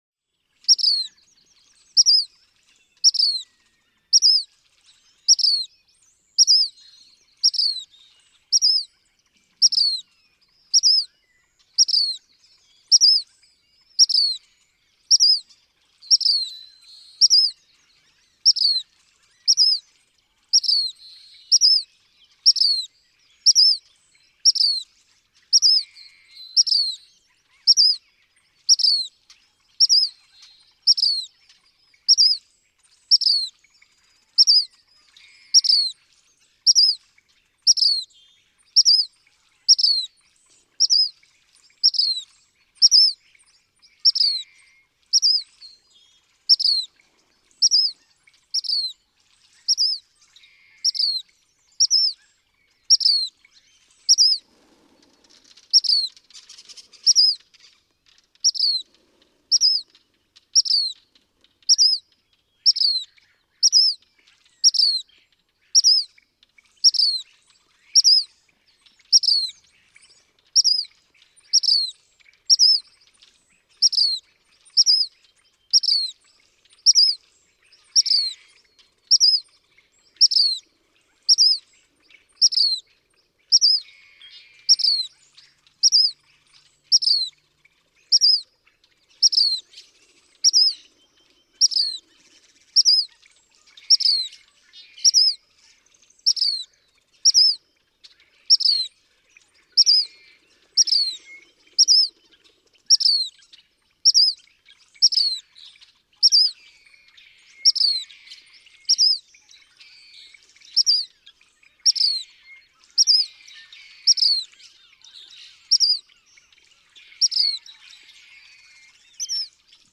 Black phoebe
Just two songs comprise his song performance, heard perhaps as tee-hee and tee-hoo. This recording is in two segments: 0:00-2:20, rapid dawn singing; 2:30-4:30, a slower pace after sunrise, all by the same individual.
Harmony Headlands State Park, Cayucos, California.
382_Black_Phoebe.mp3